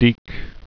(dēk)